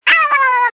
Yoshi Super Mario Sunshine 5 Sound Effect Free Download